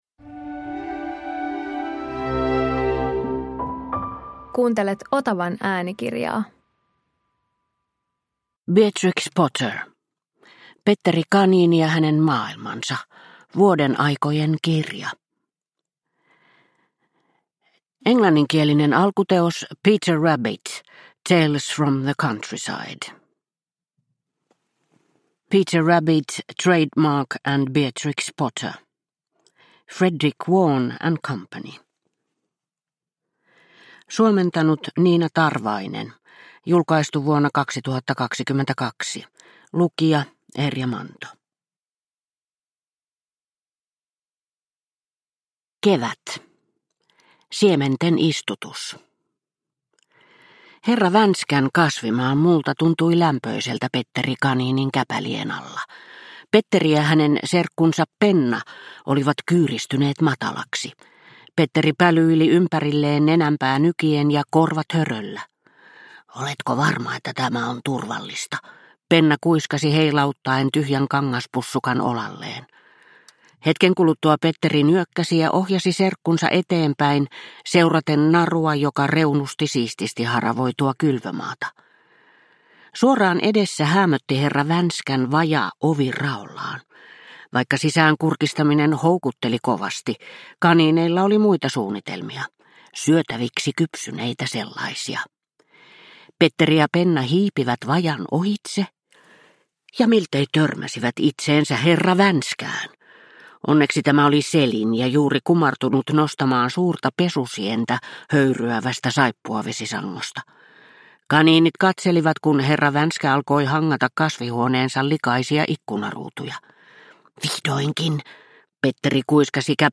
Petteri Kaniini - vuodenaikojen kirja – Ljudbok – Laddas ner